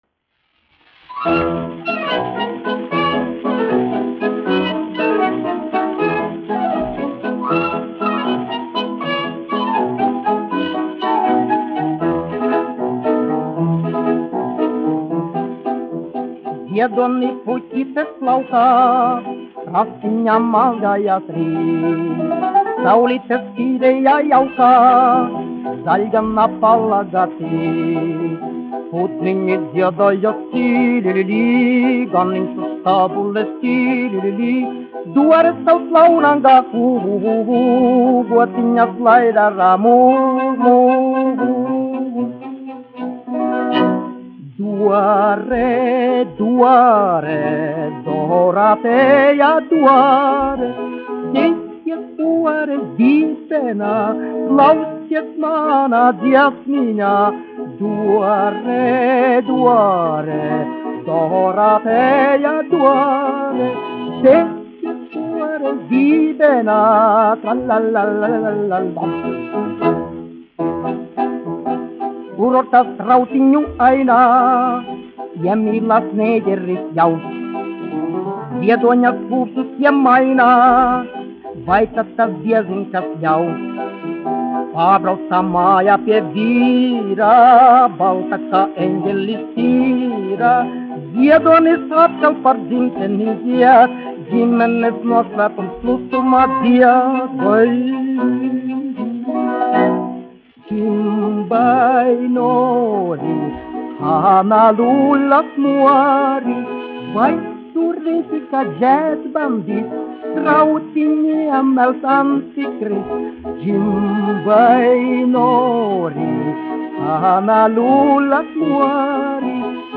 1 skpl. : analogs, 78 apgr/min, mono ; 25 cm
Populārā mūzika
Humoristiskās dziesmas
Skaņuplate
Latvijas vēsturiskie šellaka skaņuplašu ieraksti (Kolekcija)